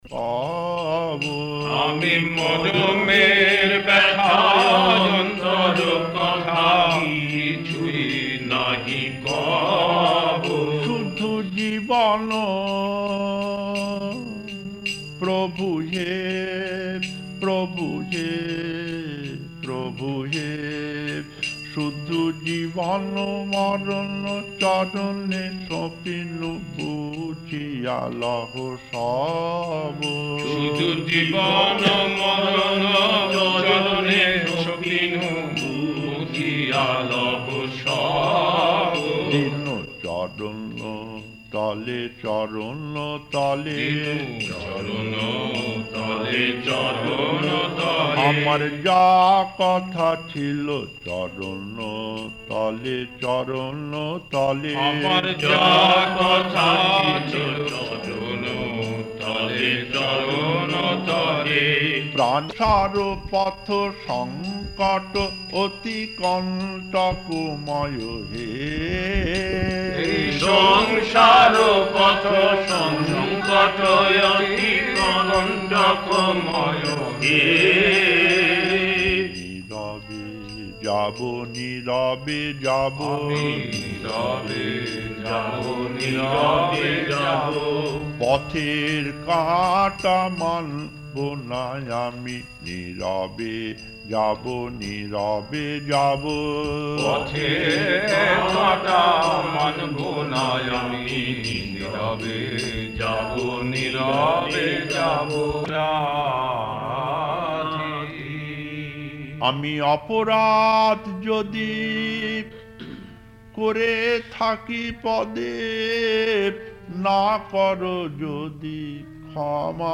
Kirtan